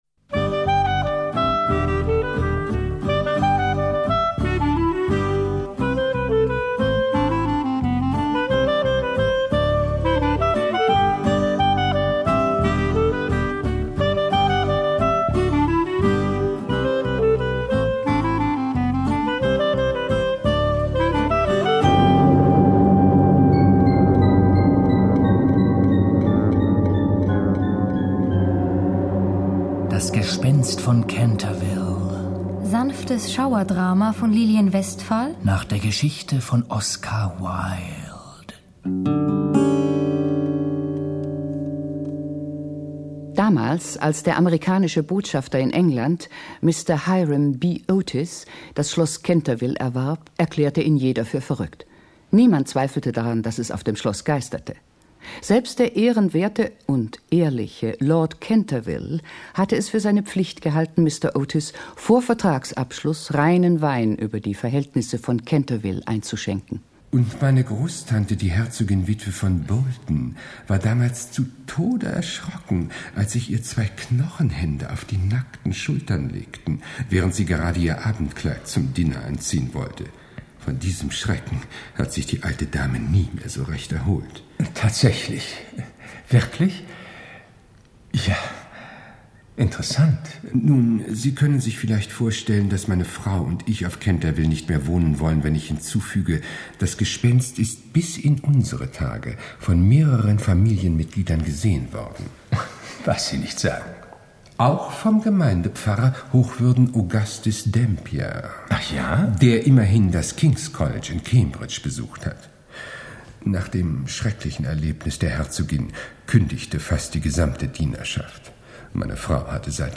Schlagworte Canterville Ghost • Gespenst • Gespenstergeschichte • Gruselhörbuch • Gruselklassiker • Hörspiel • Kinder ab 6 • Kinderbuch • Klassiker • Musik • Neuerscheinung 2023 • Schlossgeist • Spuk • Weltliteratur